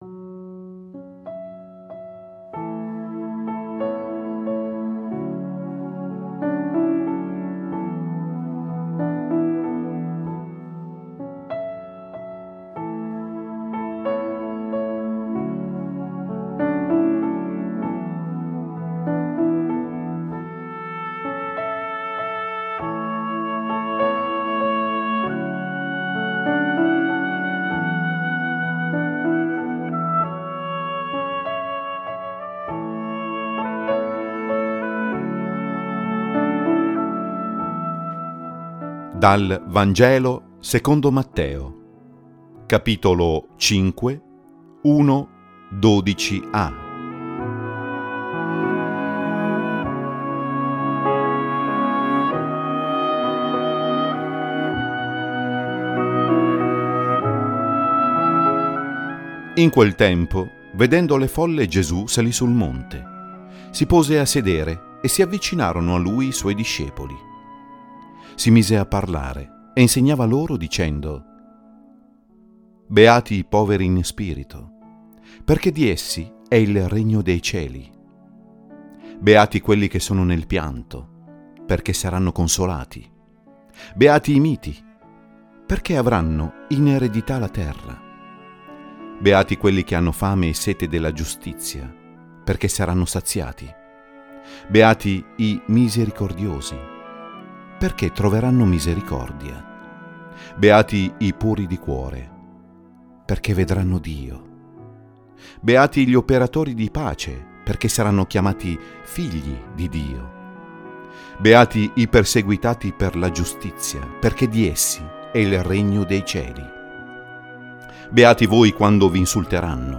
Serie: Omelia